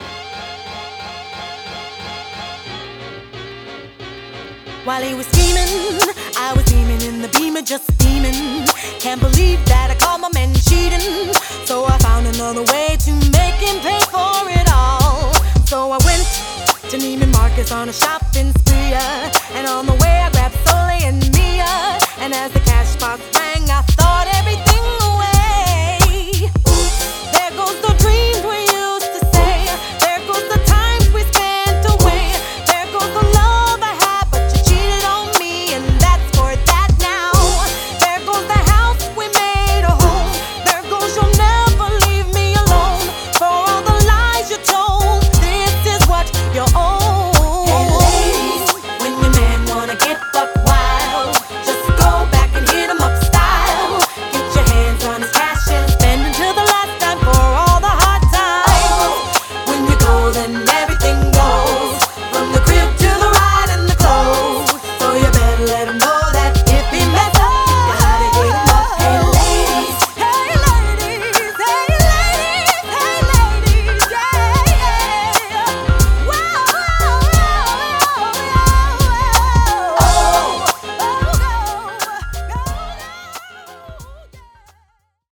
BPM90
Audio QualityPerfect (High Quality)
genre: r&b/soul